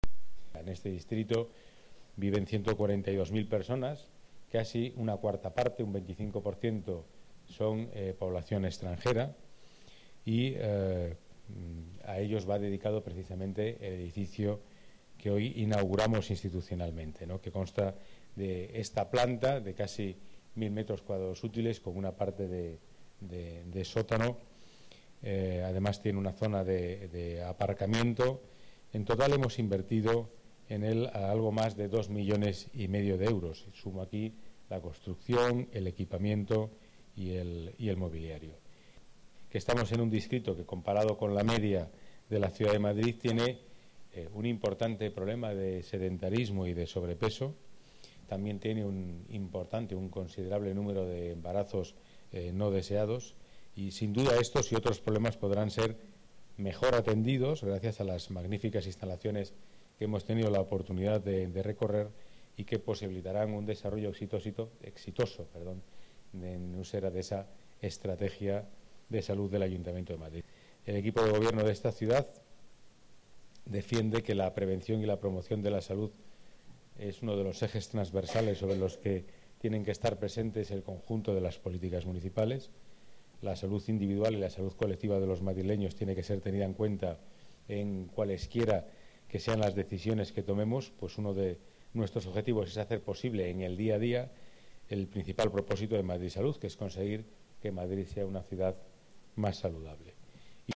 Nueva ventana:Declaraciones delegado Seguridad, Pedro Calvo: nuevo Centro Municipal de Salud Usera